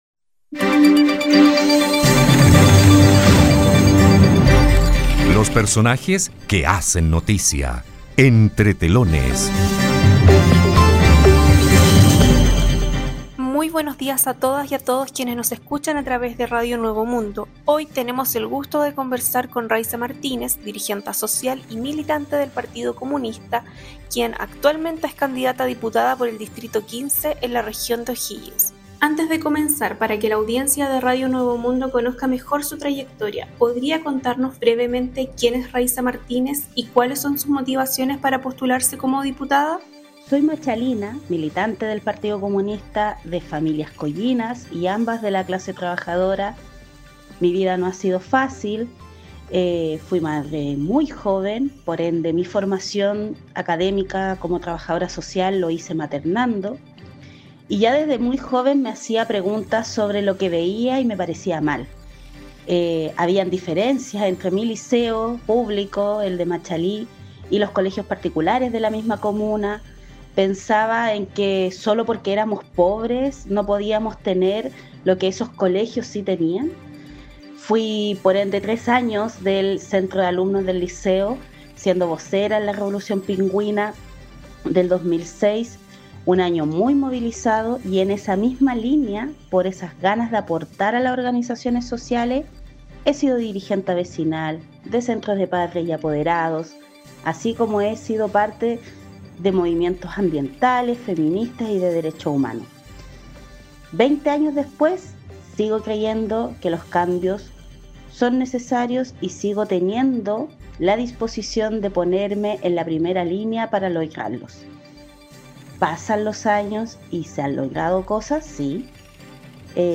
En entrevista con Radio Nuevo Mundo